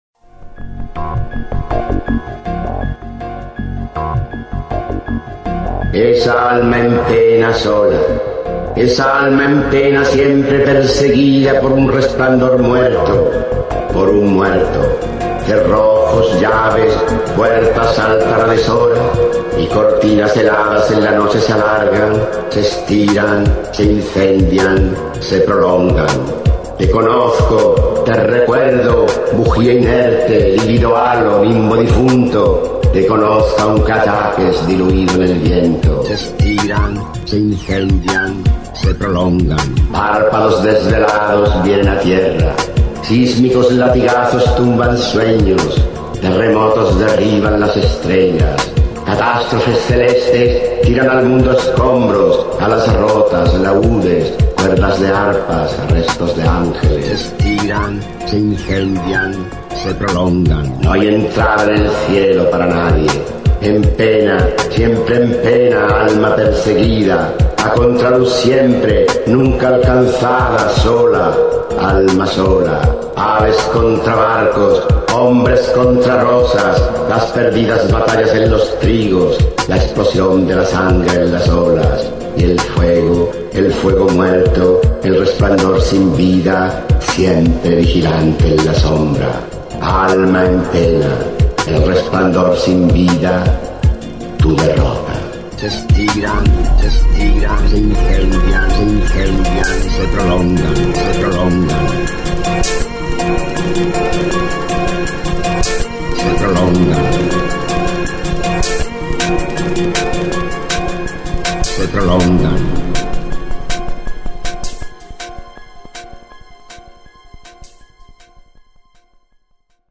Archivo de sonido con la voz del escritor español Rafael Alberti, quien recita su poema “El alma en pena" (Sobre los ángeles, 1927-1928).
Se recomienda este recurso para promover un encuentro placentero de los estudiantes con el texto literario, recitado en la voz de su autor.